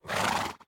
sounds / mob / horse / idle2.ogg